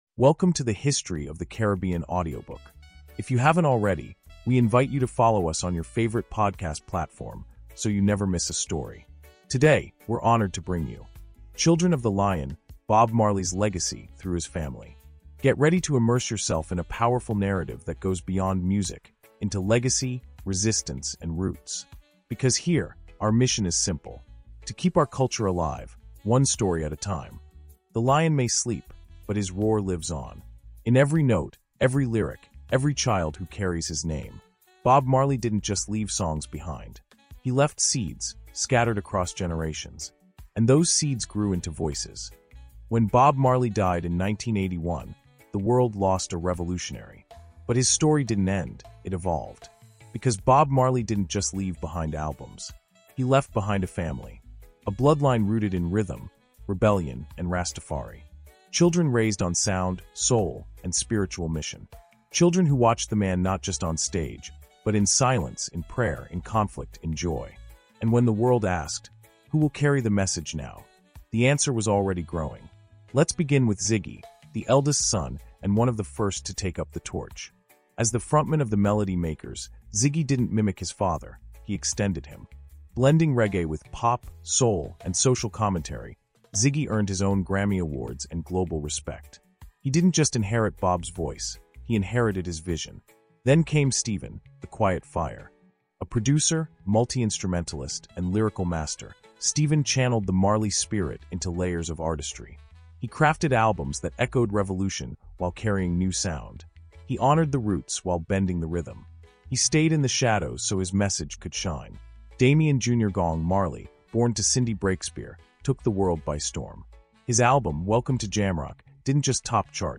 Children of the Lion – Bob Marley’s Legacy Through His Family” is a heartfelt audio reflection that explores the living legacy of Bob Marley—carried not just through songs, but through his children. This chapter honors the next generation of Marleys who have taken the torch and continued their father's mission through music, movement, and message.